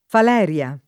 [ fal $ r L a ]